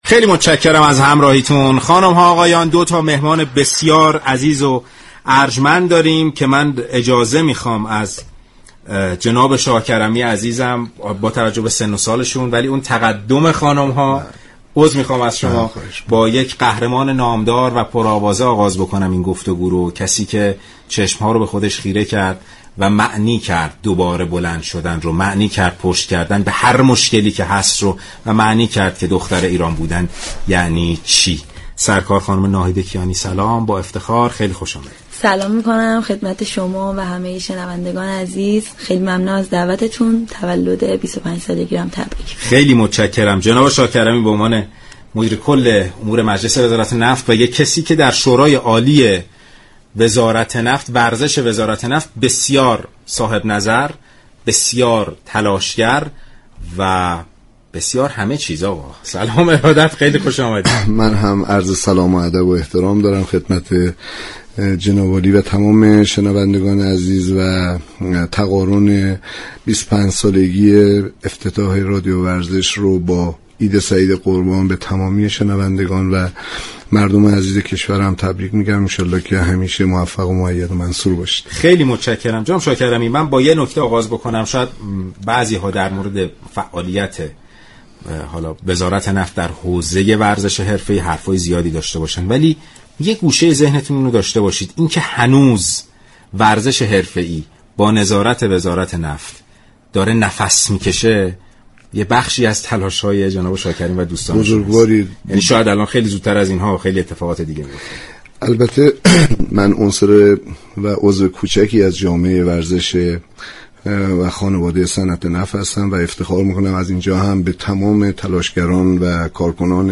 این بانوی جوان تكواندوكار در گفت‌و‌گویی صمیمانه از خاطرات رادیویی خود گفت.